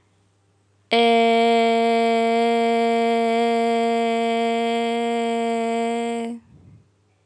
Vocale în format .wav - Vorbitorul #20